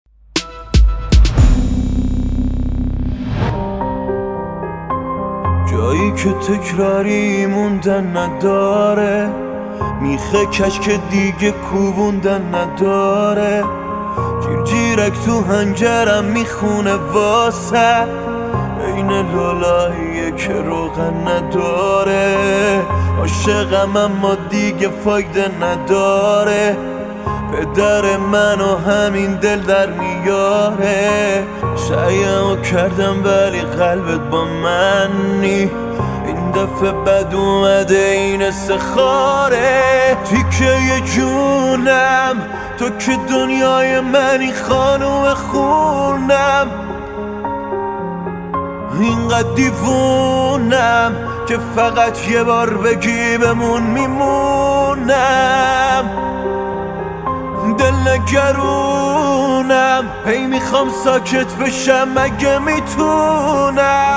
اجرای زنده پیانو ورژن